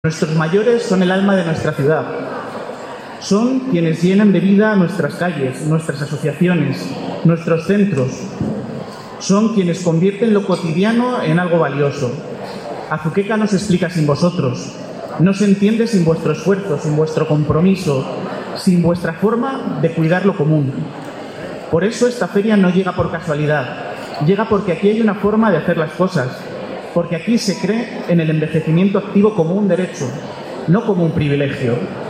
Declaraciones del alcalde Miguel Óscar Aparicio 2
El presidente regional y el alcalde de Azuqueca han asistido este viernes a la inauguración de la primera Feria del Mayor Activo